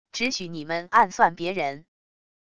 只许你们暗算别人wav音频生成系统WAV Audio Player